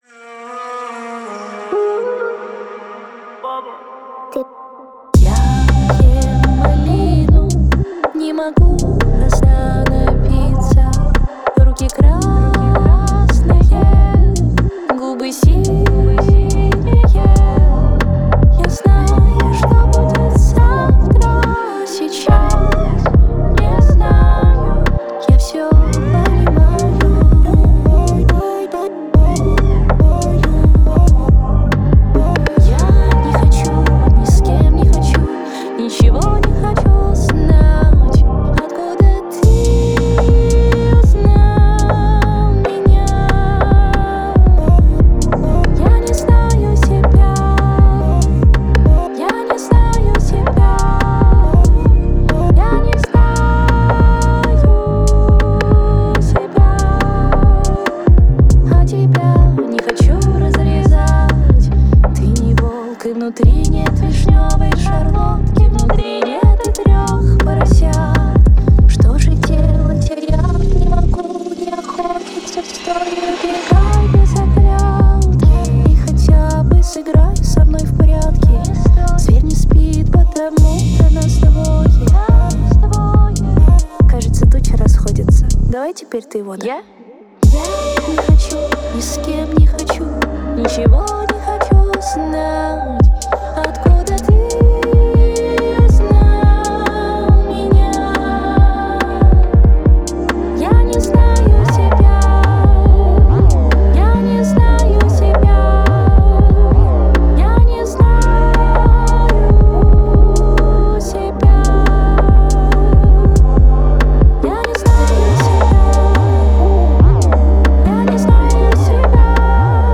Поп-музыка
Жанр: Русская музыка